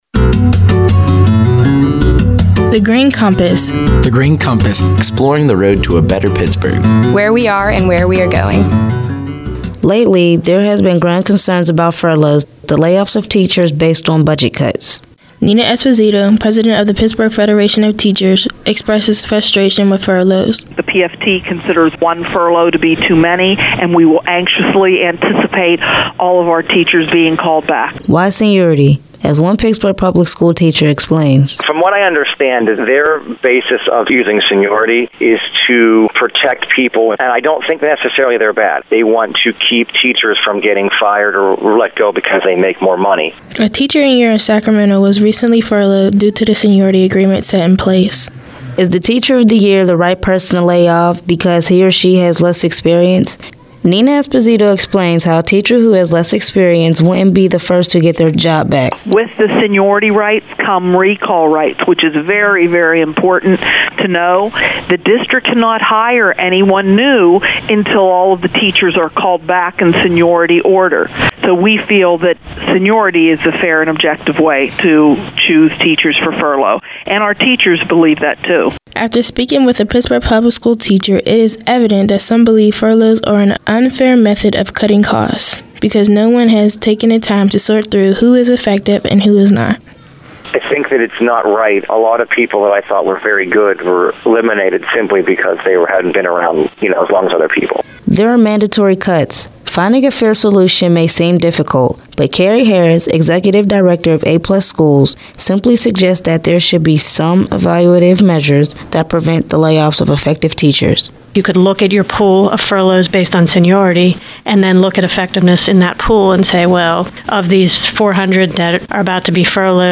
In July 2012, twenty-three students about to enter their first year of college created these nine radio features as Summer Youth Philanthropy Interns at The Heinz Endowments.
interview